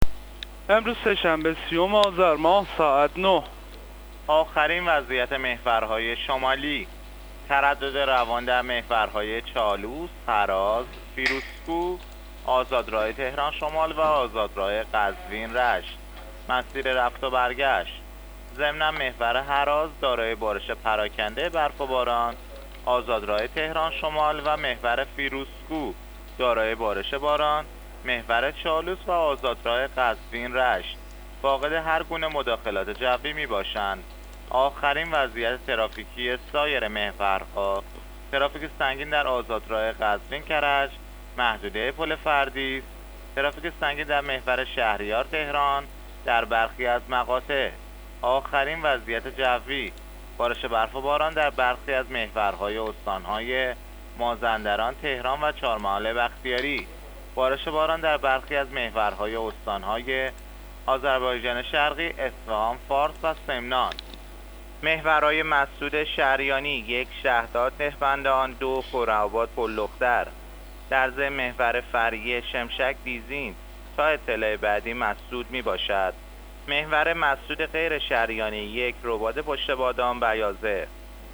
گزارش رادیو اینترنتی از آخرین وضعیت ترافیکی جاده‌ها تا ساعت ۹ سی‌ام آذر؛